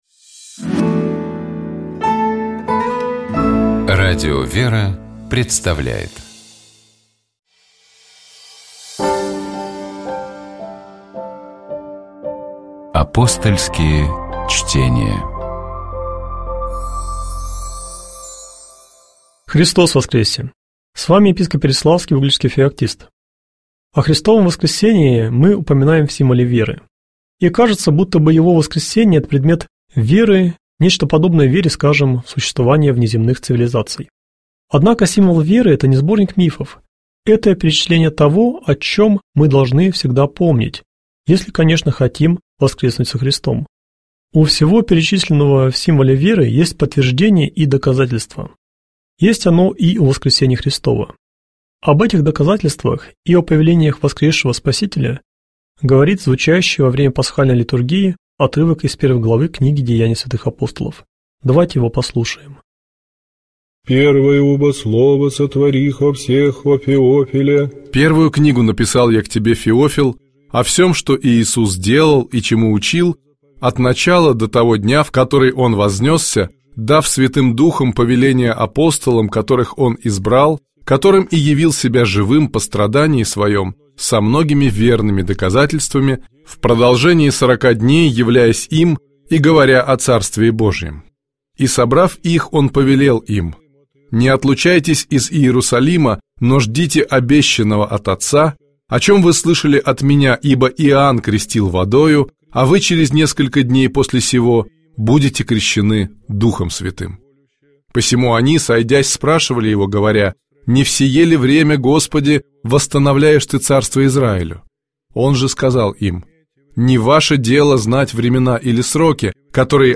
В нашей студии